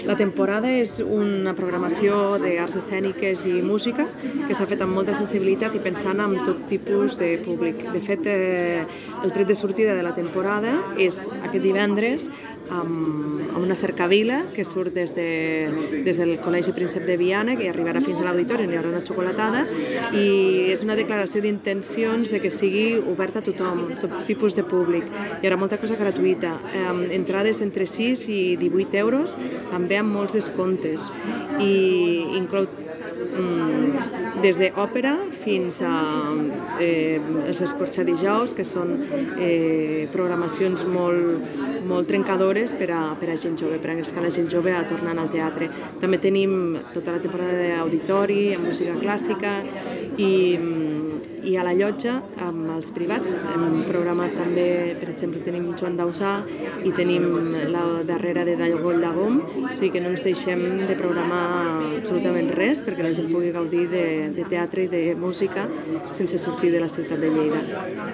Ficheros relacionados Tall de veu de la regidora de cultura, Pilar Bosch, sobre la programació de tardor de LaTemporada Lleida (1.0 MB) Presentació en pdf de la programació de LaTemporada 2023 (3.9 MB)